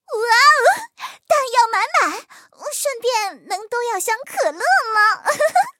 M4谢尔曼补给语音.OGG